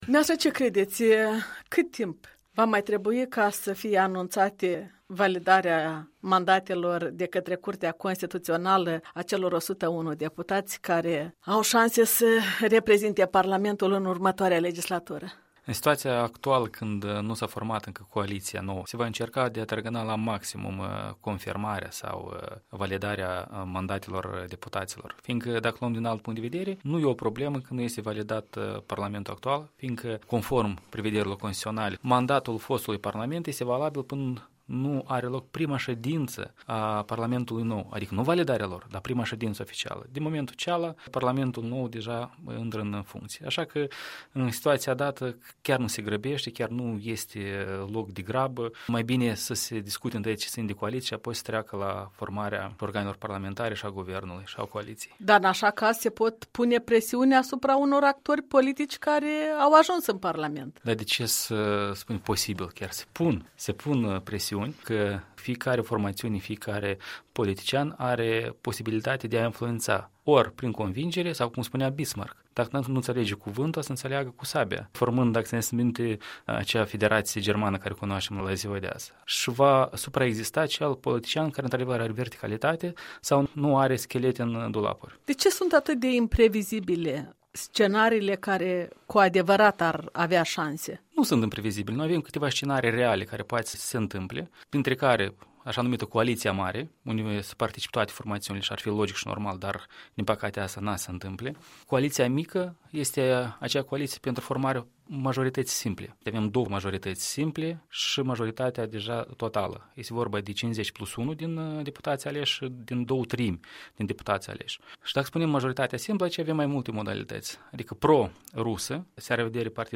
Un interviu cu un analist politic pe tema jocului post-electoral făcut de PS.